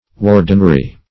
Search Result for " wardenry" : The Collaborative International Dictionary of English v.0.48: Wardenry \Ward"en*ry\, Wardenship \Ward"en*ship\, n. The office or jurisdiction of a warden.